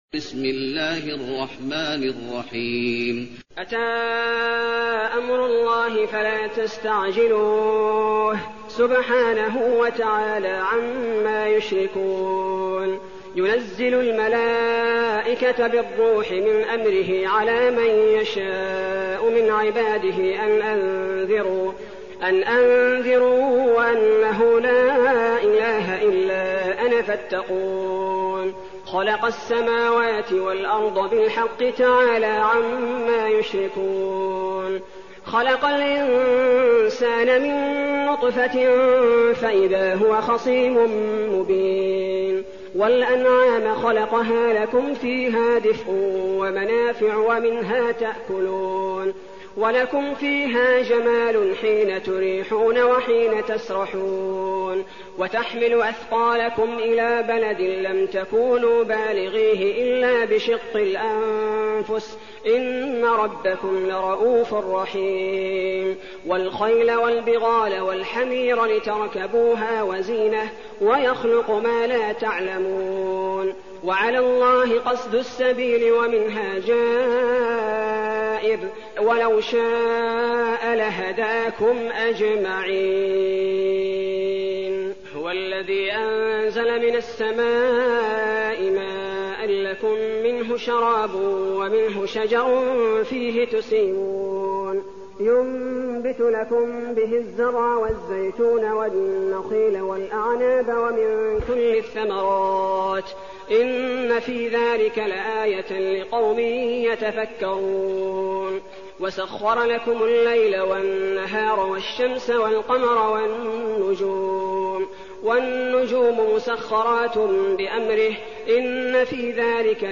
المكان: المسجد النبوي النحل